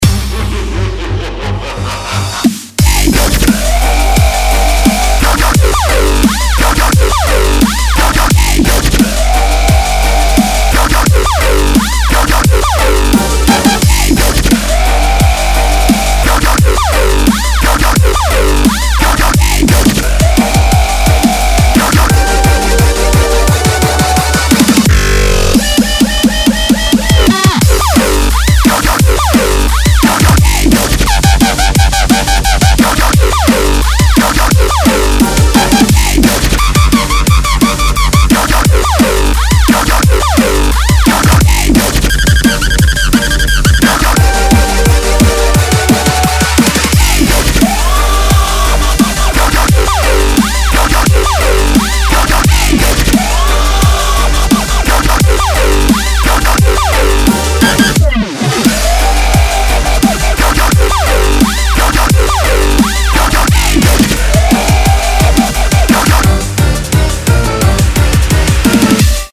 • Качество: 192, Stereo